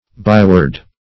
Byword \By"word`\, n. [AS. b["i]word; b["i], E. by + word.]